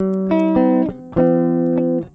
gtr_10.wav